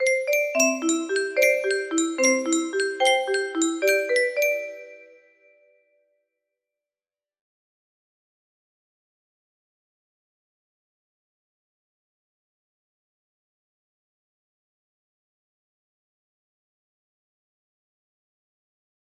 x2 music box melody